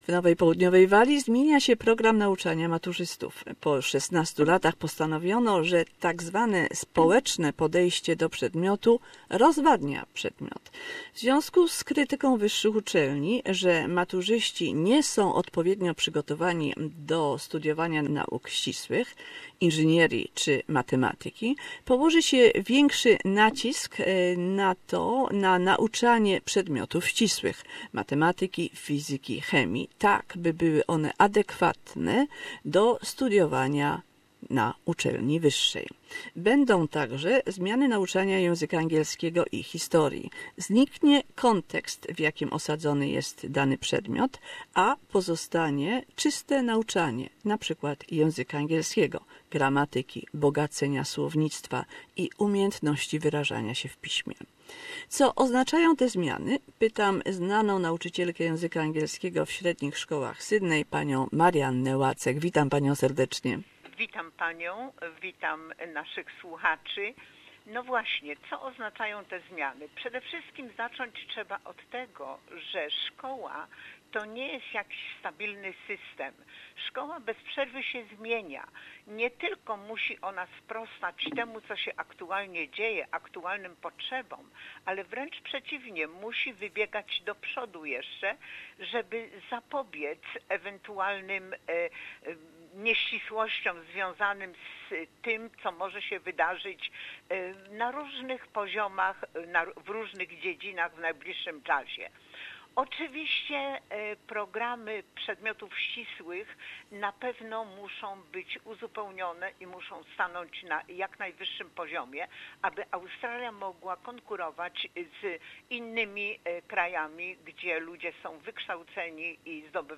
How good is the knowledge of one language for learning the next? Conversation